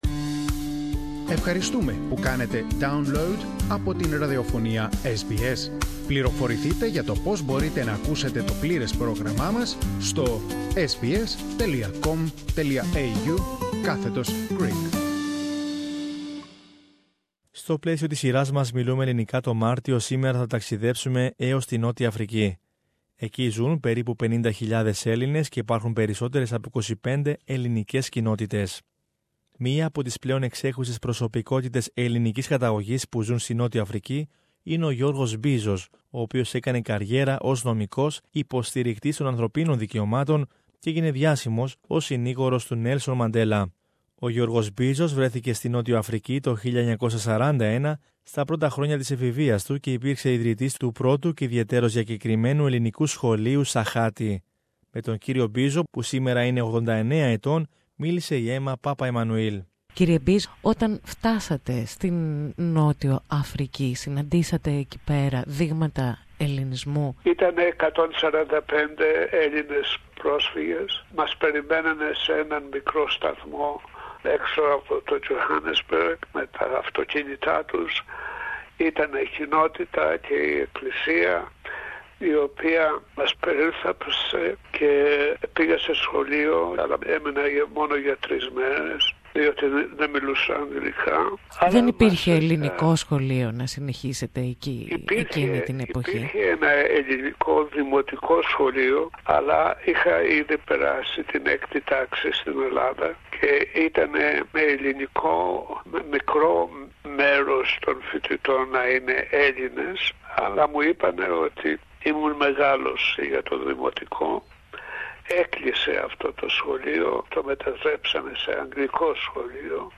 spoke with George Bizos, prominent human rights lawyer who campaigned against apartheid in South Africa.